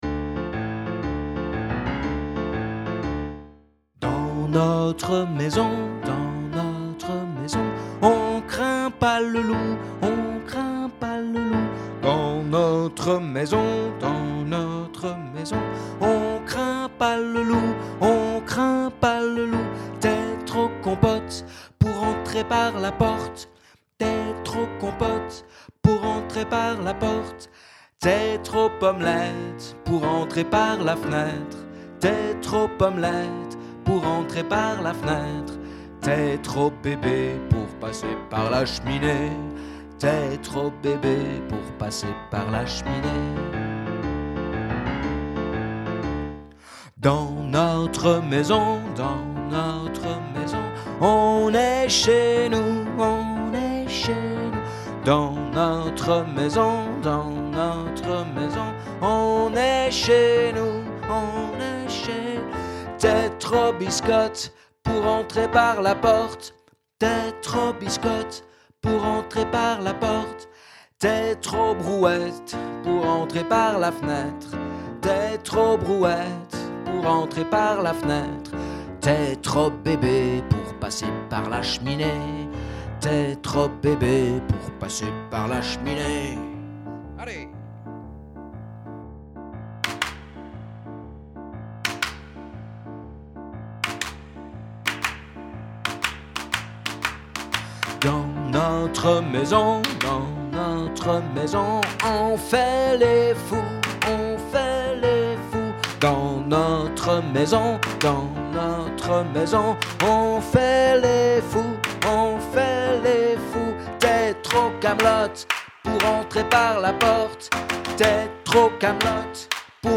Mélodie voix 1
comptine_voix1.mp3